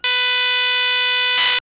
With this cable the notebook can store CSAVE data as WAV files and such a WAV file can be played for restoring the content.
For those of us, that like to hear that original sound, I offer a sample here: